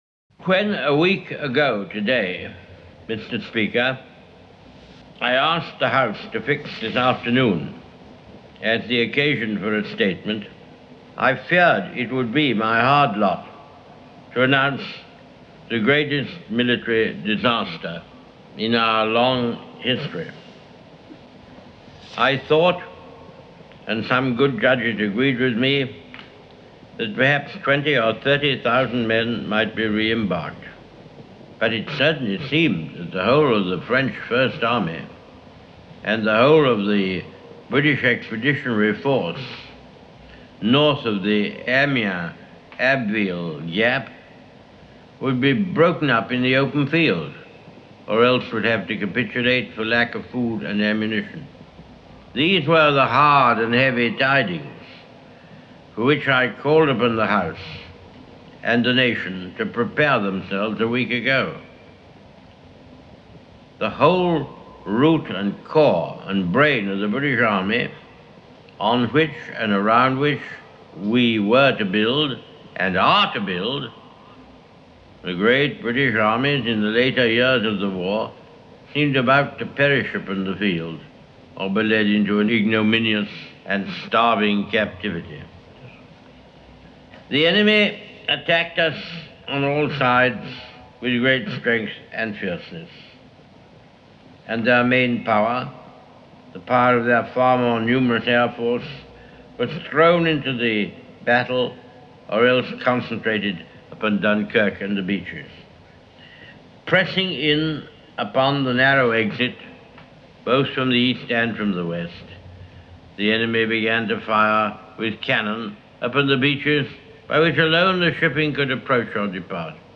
As Prime Minister Winston Churchill advised early in this speech given June 4th, 1940 in the House of Commons, wars are not won by evacuations.